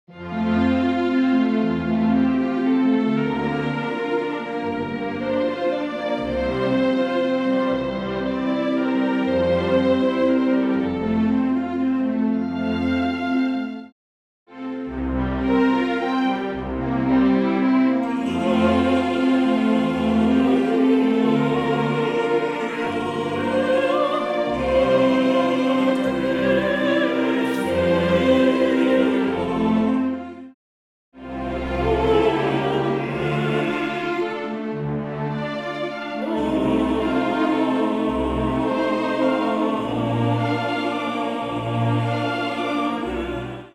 with chorus
full orchestral accompaniment